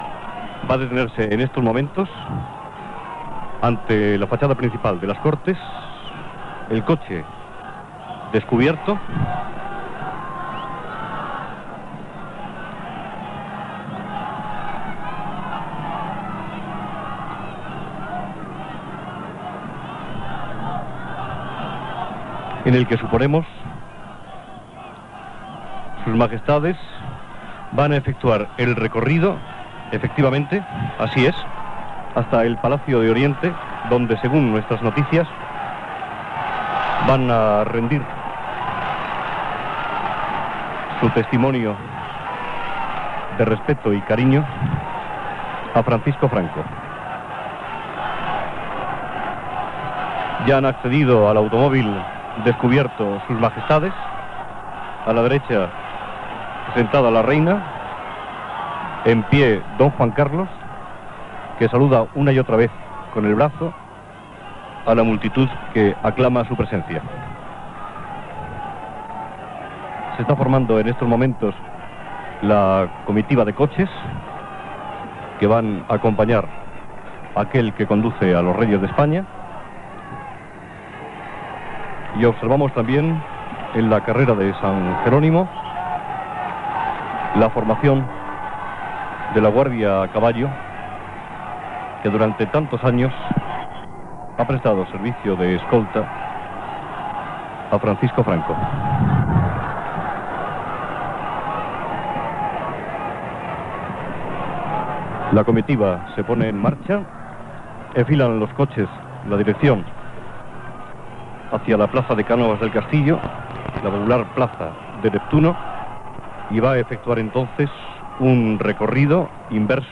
Transmissió de la coronació del rei Juan Carlos I. Els reis d'Espanya surten de l'edifici del Palacio de las Cortes, a la carrera de San Jerónimo de Madrid, per dirigir-se al Palacio Nacional
Informatiu